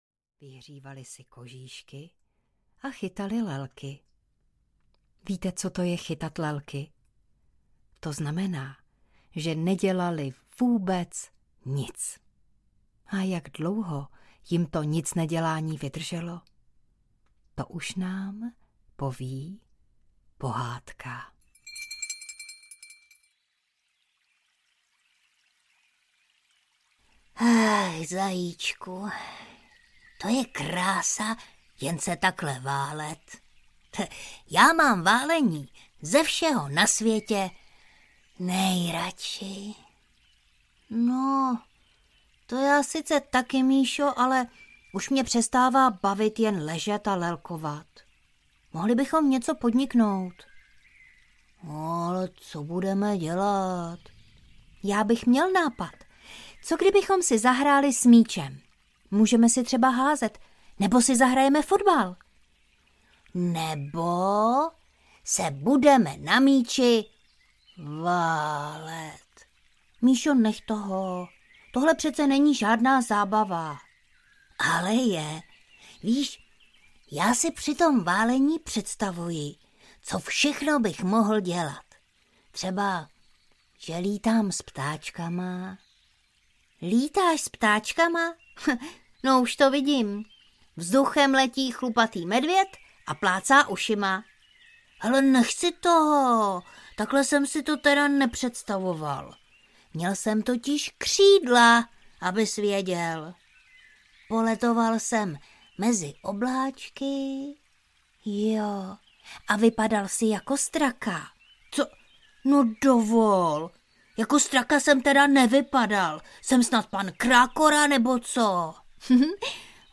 Medvědi Míša a Máša táboří audiokniha
Ukázka z knihy